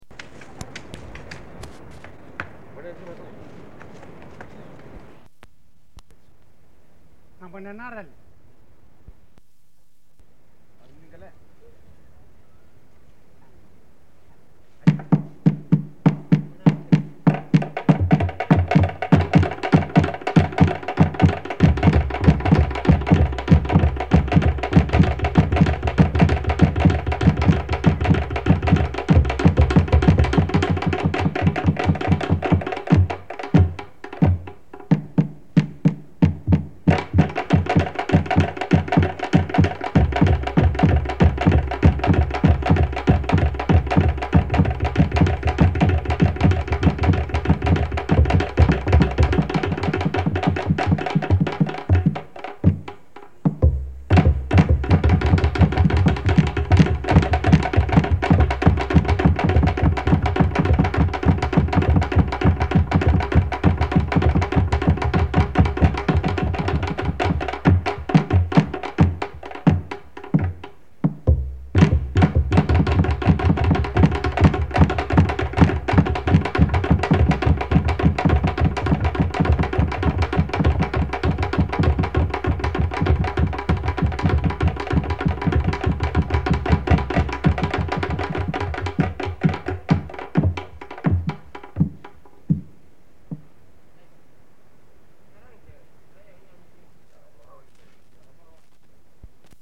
Drumming at Wor Tamat dancing ground
reel-to-reel tape recordings of music and soundscapes
mainly on the island of Malekula (Malampa Province) in Vanuatu between 1960 and 1979.